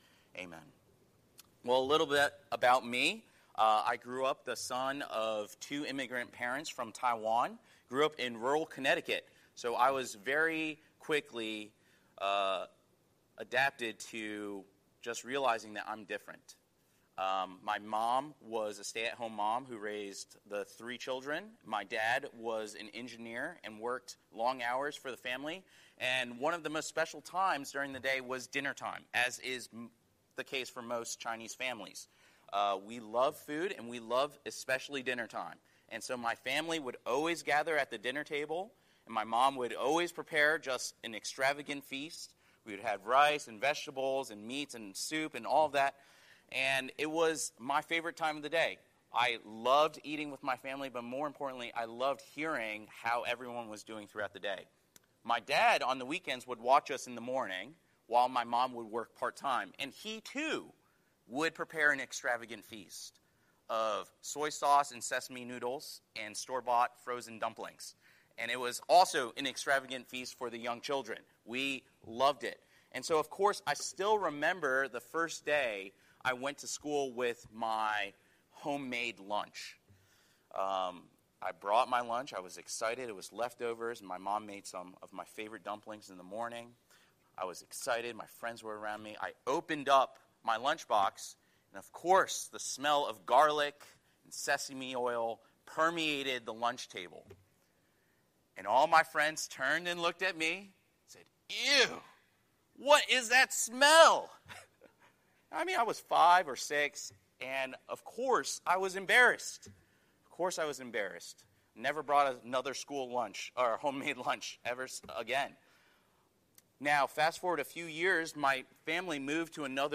Scripture: Luke 10:25–37 Series: Sunday Sermon